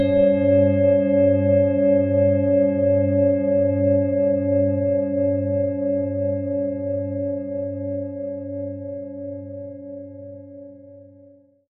Wie klingt diese tibetische Klangschale mit dem Planetenton Pluto?
PlanetentonPluto & Biorhythmus Körper (Höchster Ton)
HerstellungIn Handarbeit getrieben
MaterialBronze